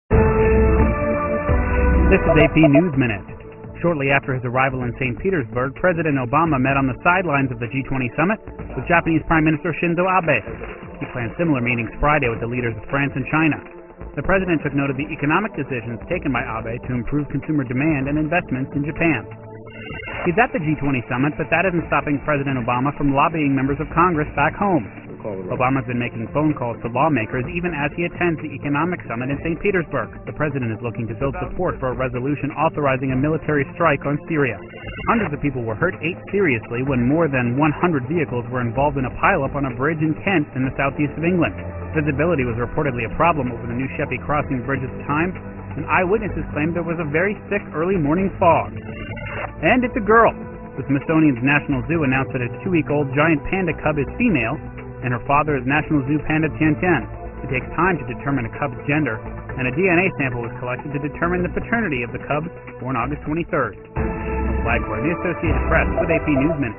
在线英语听力室美联社新闻一分钟 AP 2013-09-10的听力文件下载,美联社新闻一分钟2013,英语听力,英语新闻,英语MP3 由美联社编辑的一分钟国际电视新闻，报道每天发生的重大国际事件。电视新闻片长一分钟，一般包括五个小段，简明扼要，语言规范，便于大家快速了解世界大事。